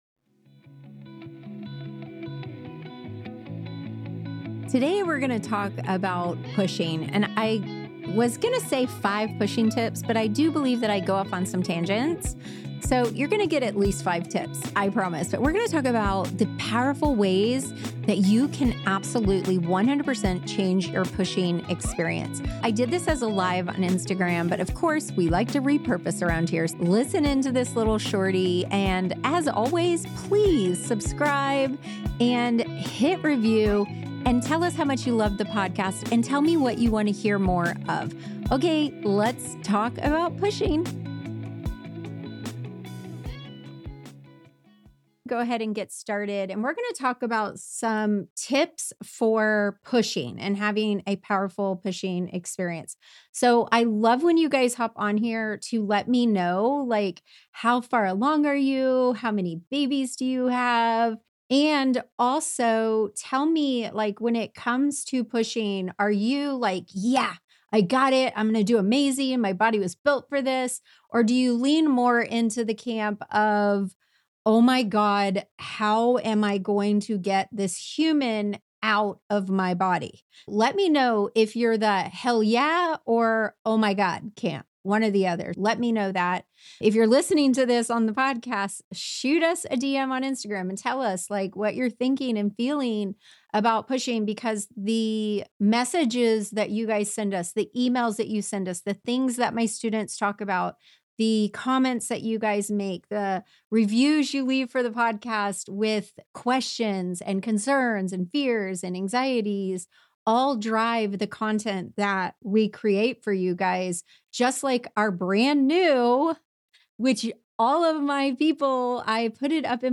ig-live-pushing-tips.mp3